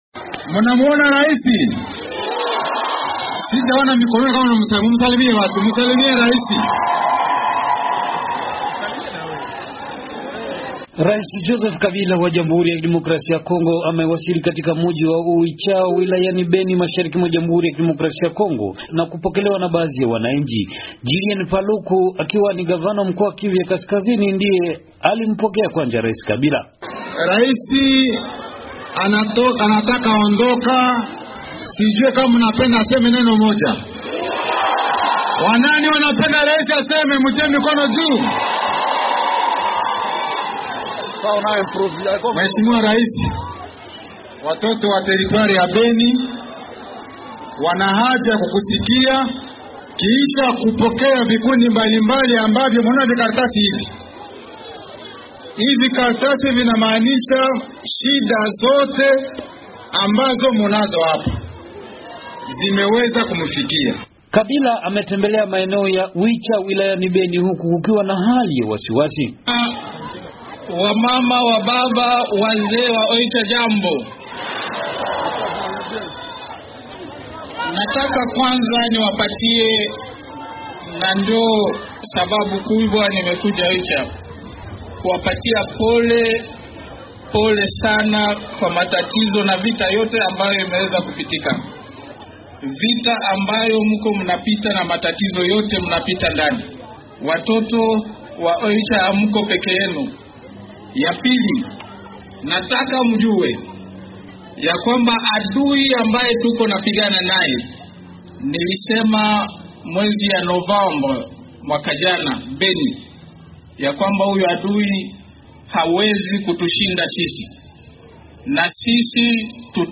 Unaweza kusikiliza taarifa ya mwandishi wetu